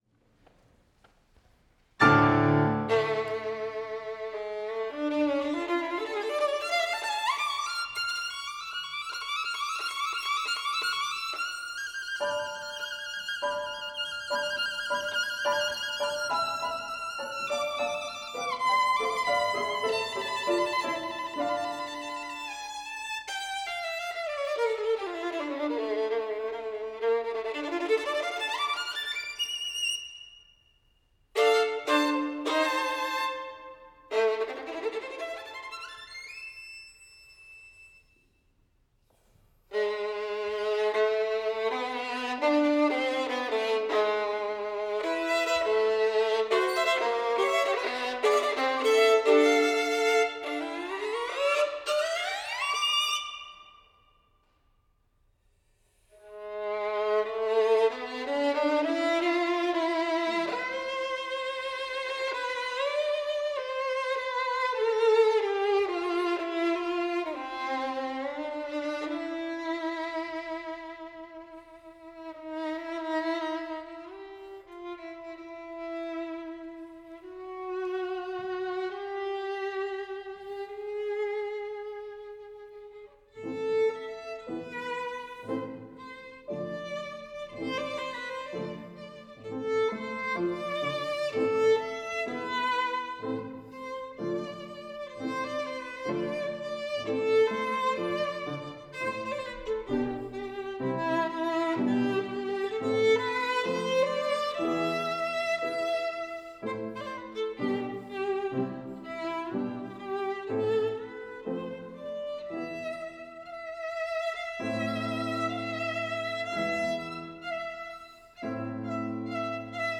violin
piano